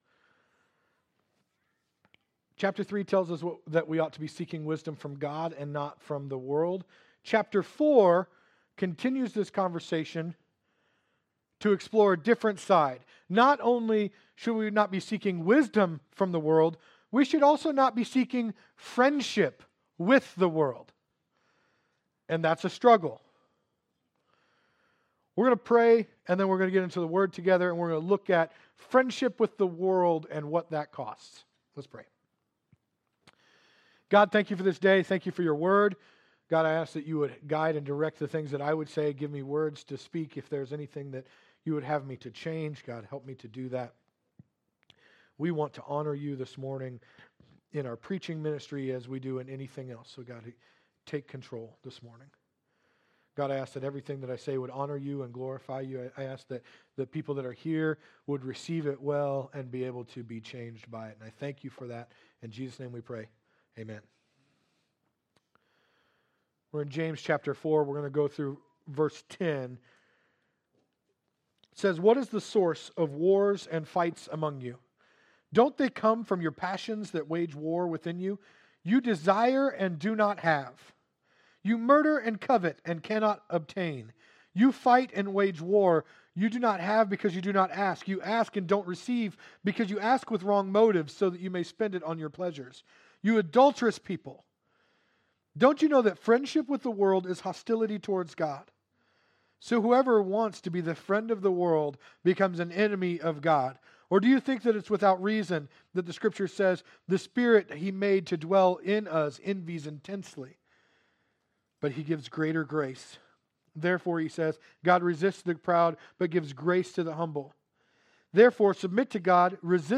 Sermons | Calvary Foursquare Church